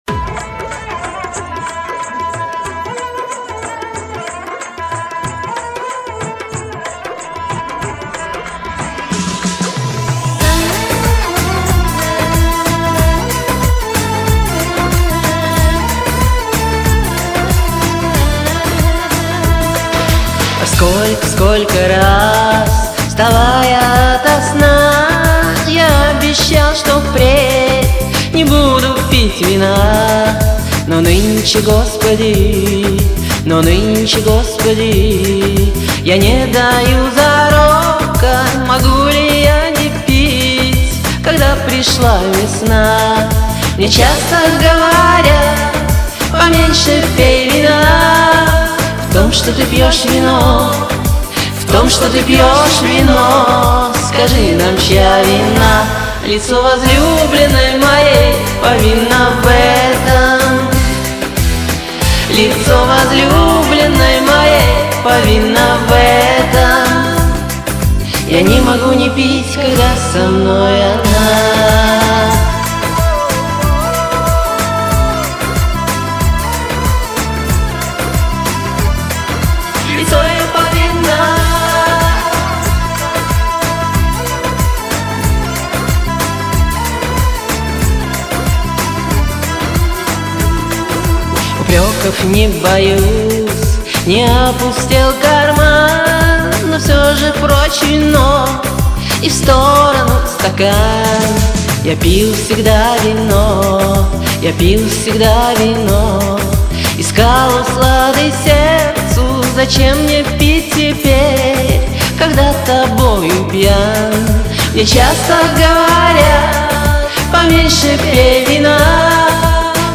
Комментарий инициатора: Приглашаю любителей восточной песни.